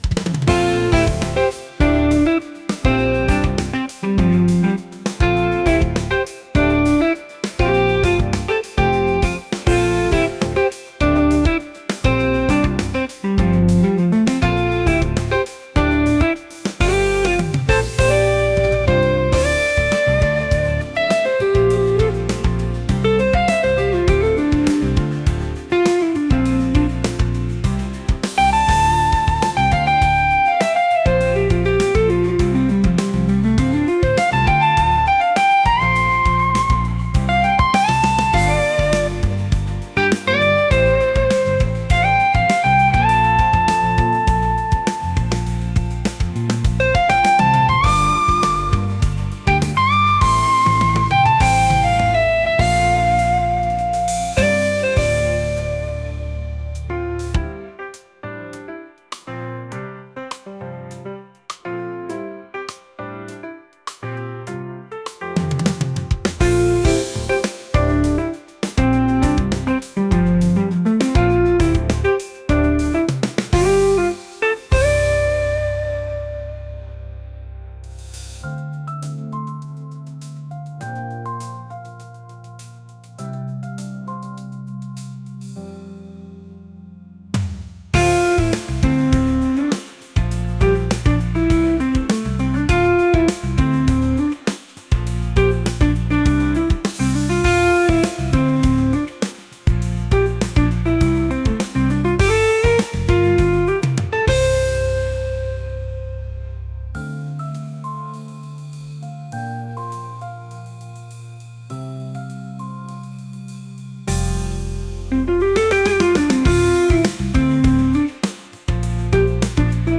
jazz | fusion | energetic